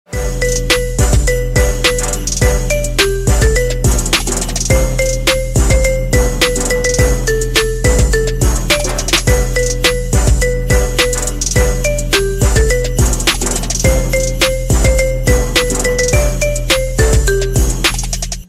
Kategorie Świąteczne